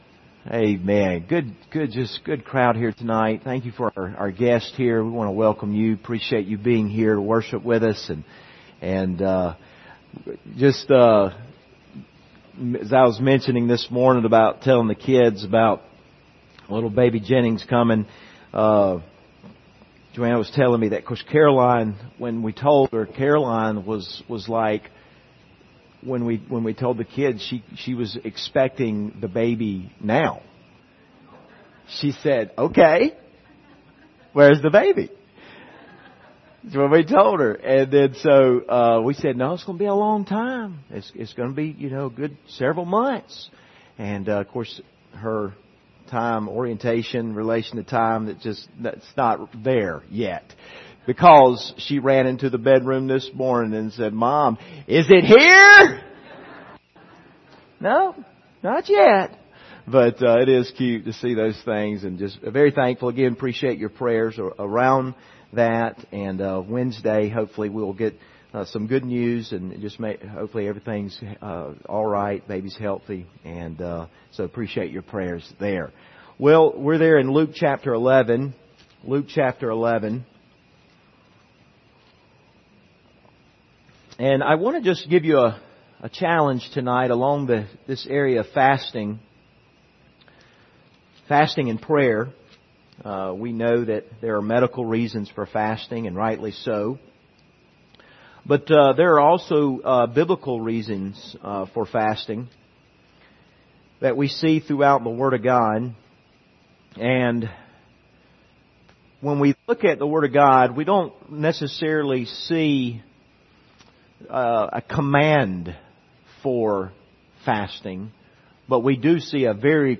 Service Type: Sunday Evening Topics: fasting